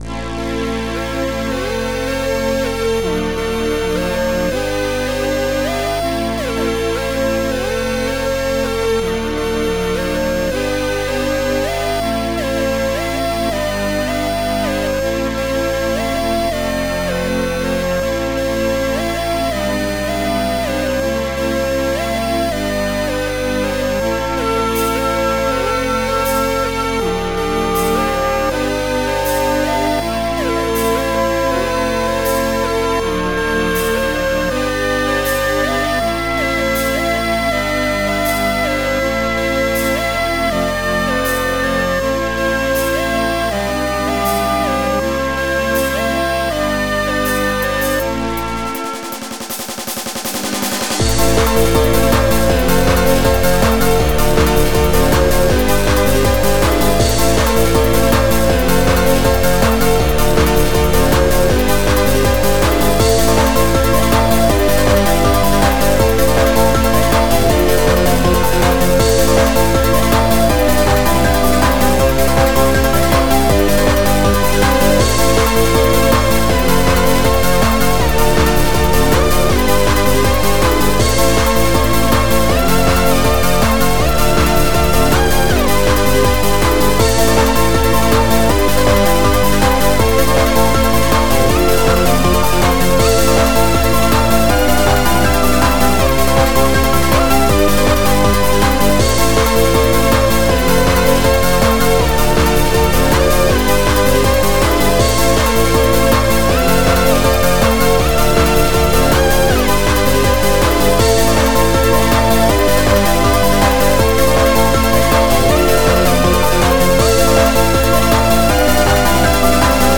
Extended Module